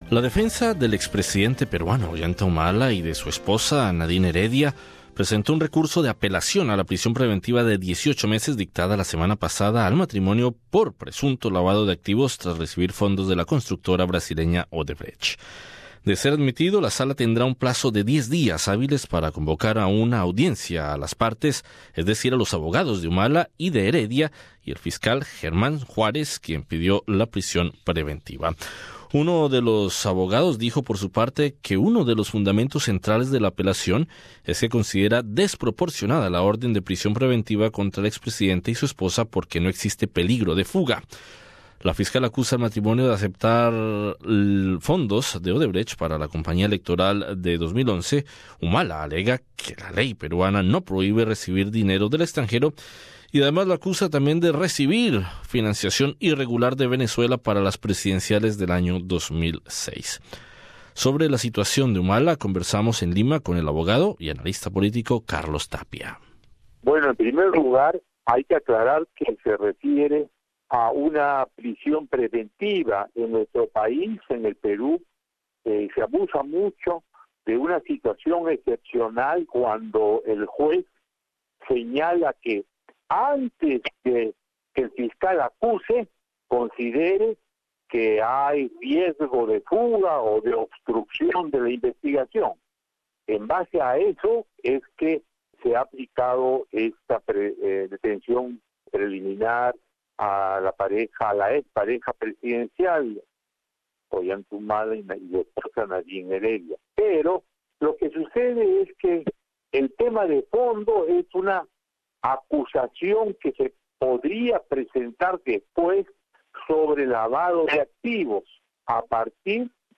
Entrevistado: el analista político